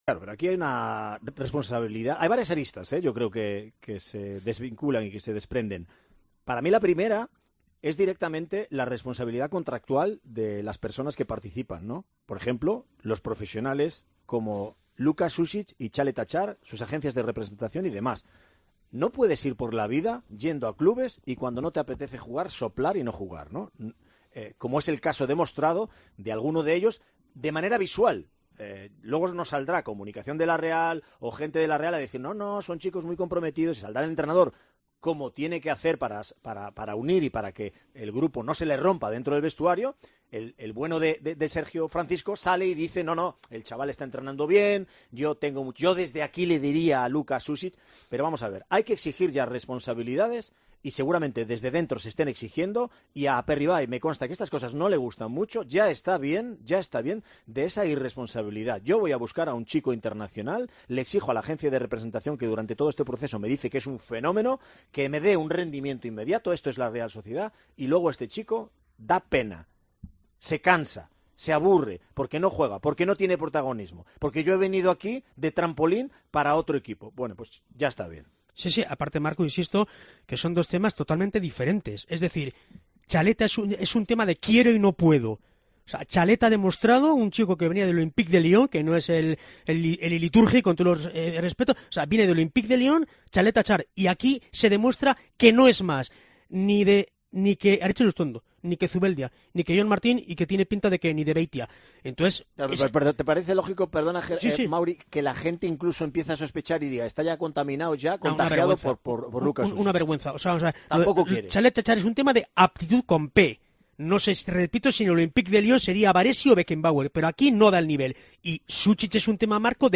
Intenso debate sobre el bajo rendimiento de los fichajes de la Real Sociedad y la gestión de la dirección deportiva en 'Tiempo de Txoko'.